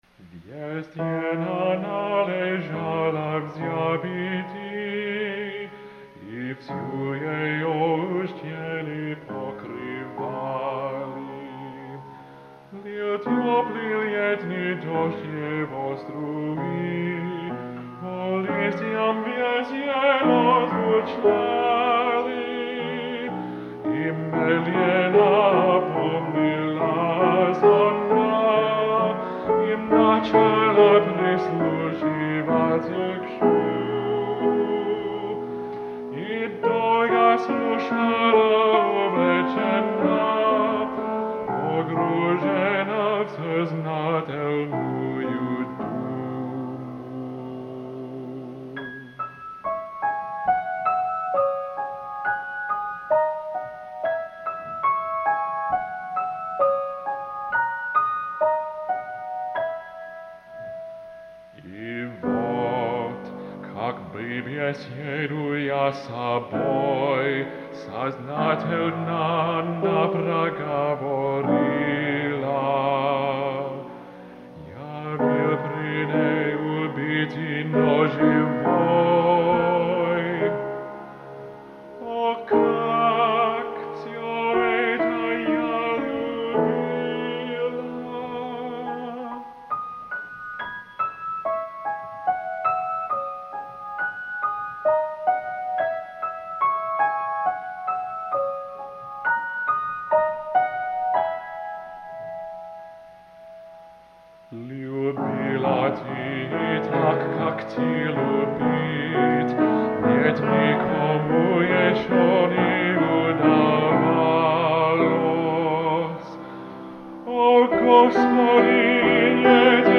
for baritone and piano